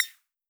4scroll.wav